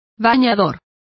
Complete with pronunciation of the translation of bather.